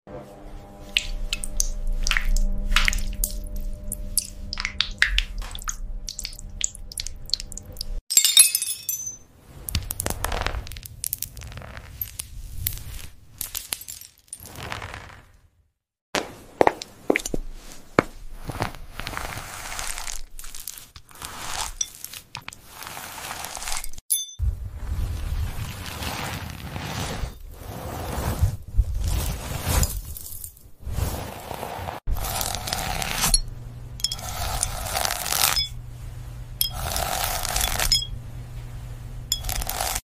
Enjoy Some Glass Hair ASMR Sound Effects Free Download